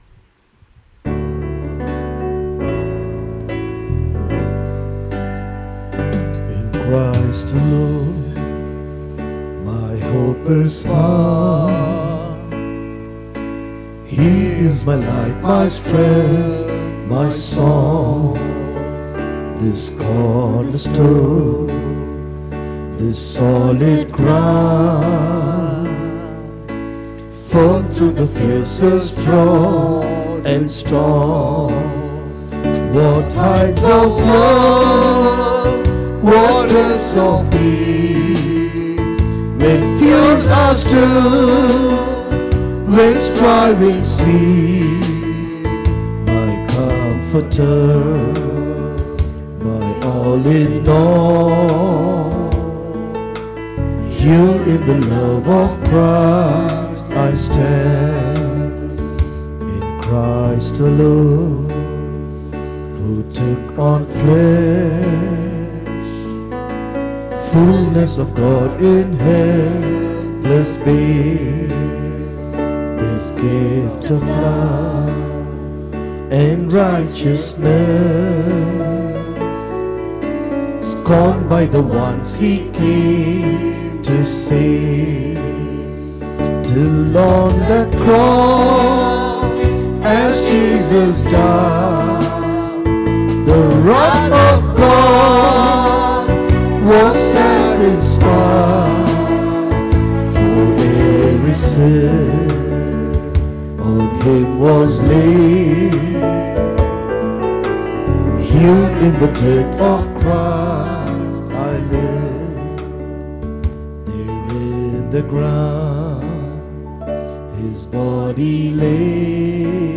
Acts 16:26 Service Type: Sunday Morning Service Download